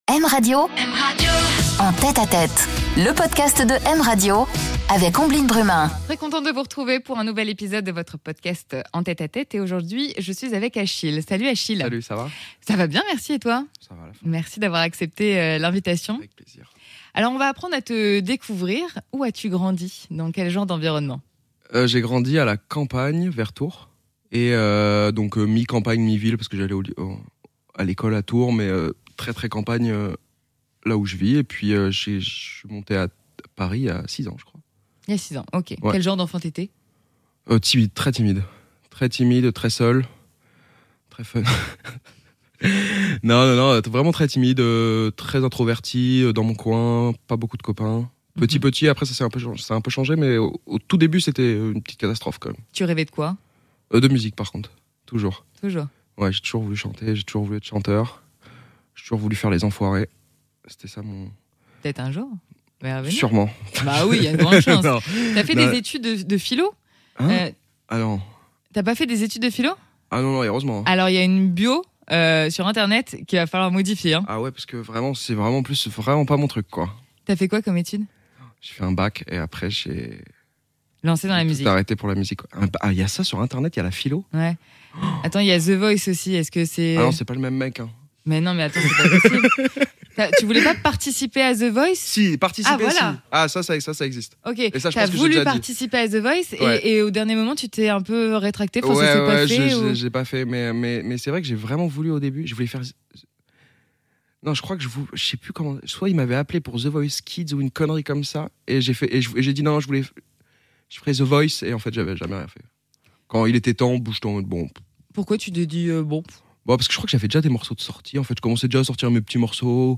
Il nous interprète aussi son titre en live !